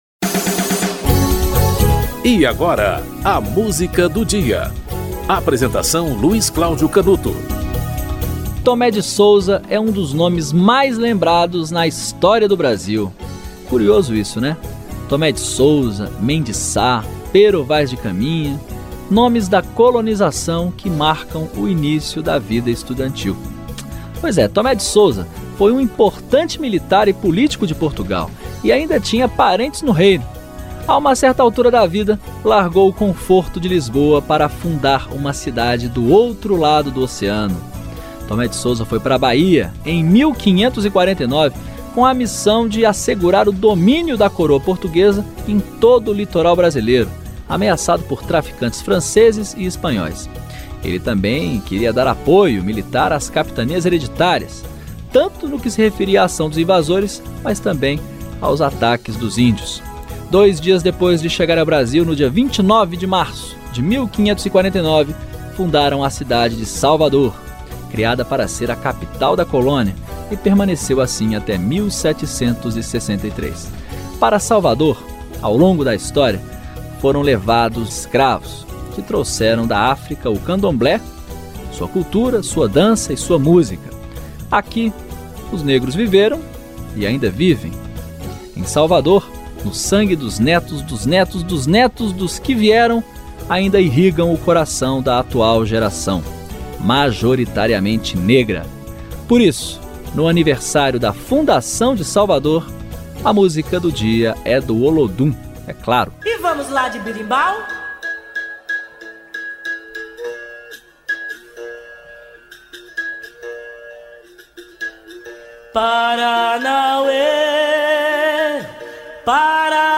Produção e apresentação